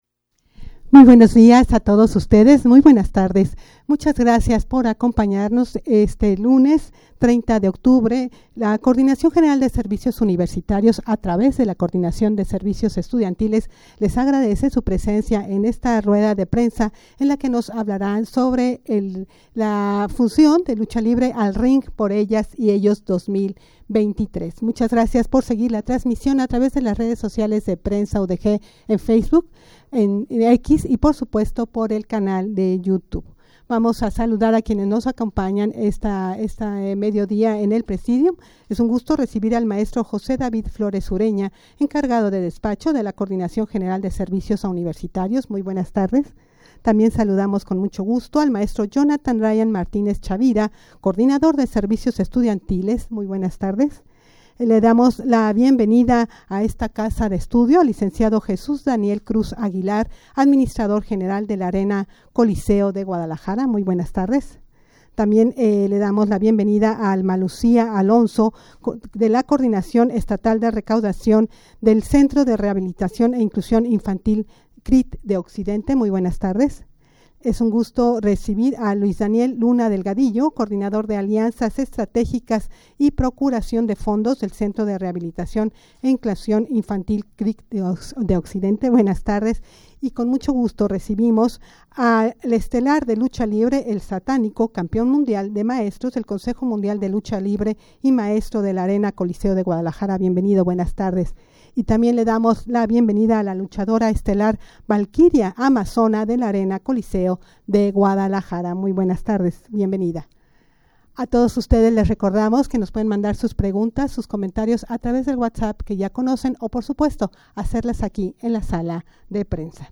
rueda-de-prensa-al-ring-por-ellas-y-ellos-2023-lucha-en-beneficio-de-la-salud-de-ninas-ninos-y-adolescentes.mp3